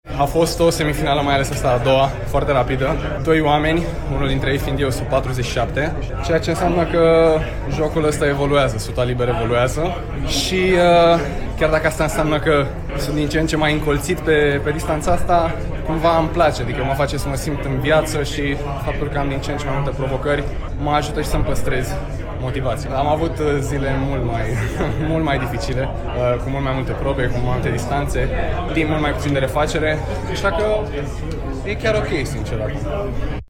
David Popovici, la televiziunea națională: „Faptul că am din ce în ce mai multe provocări mă ajută și să-mi păstrez motivația”